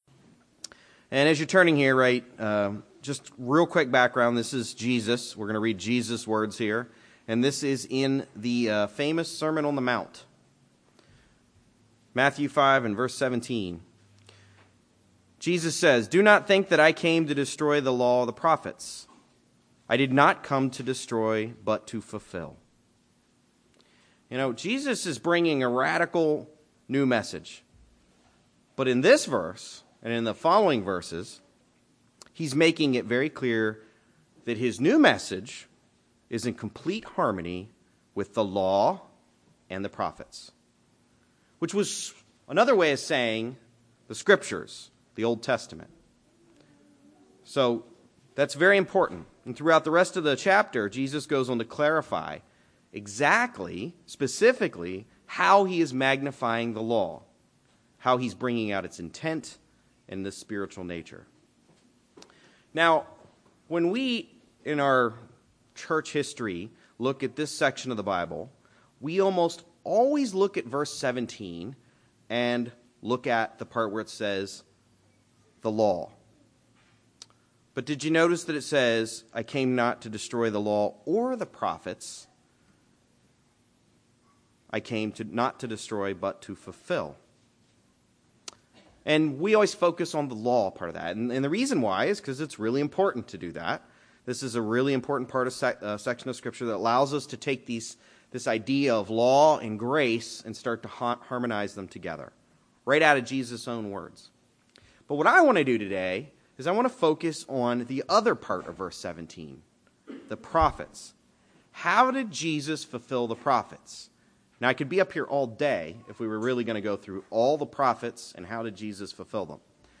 Sermons
Given in Greensboro, NC Raleigh, NC